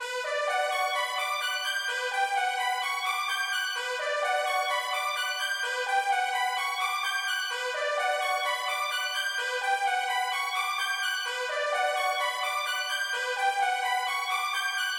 描述：使用fruitity loops的vst南部铜管乐器
Tag: 128 bpm Trap Loops Brass Loops 2.52 MB wav Key : Unknown